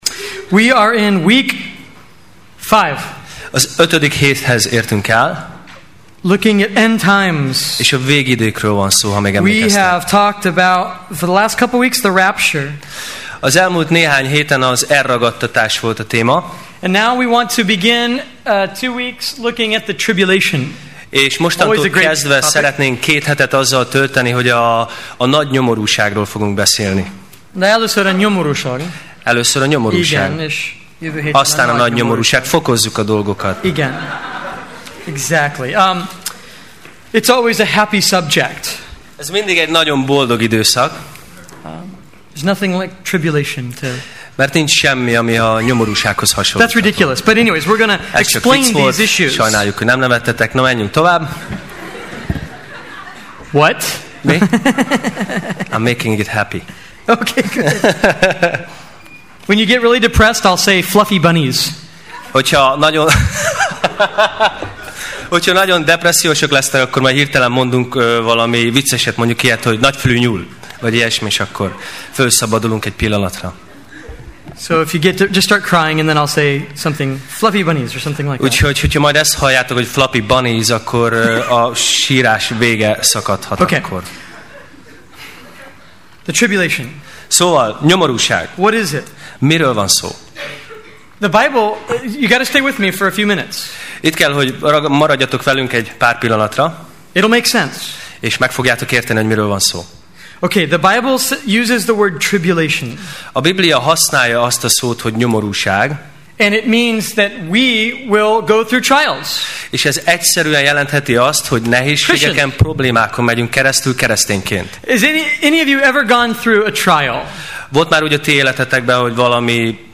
Sorozat: Tematikus tanítás Alkalom: Vasárnap Este